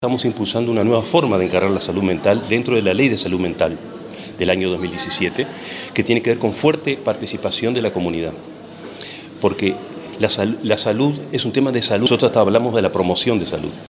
Se realizó la primera conferencia preparatoria Salud mental y abordajes comunitarios, organizada por la Universidad de la República y Apex y coorganizada por la Intendencia de Canelones a través de la Dirección de Salud, entre otras instituciones.